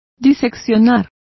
Complete with pronunciation of the translation of dissect.